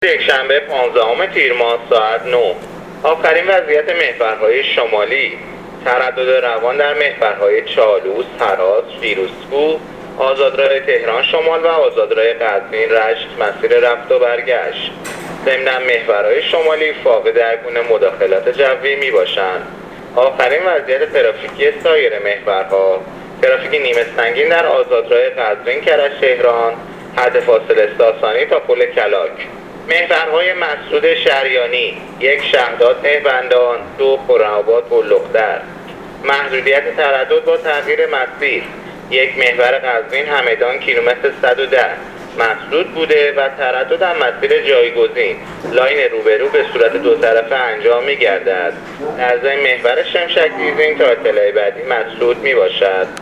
گزارش رادیو اینترنتی از وضعیت ترافیکی جاده‌ها تا ساعت ۹ یکشنبه ۱۵ تیر